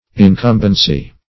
Incumbency \In*cum"ben*cy\, n.; pl. Incumbencies. [From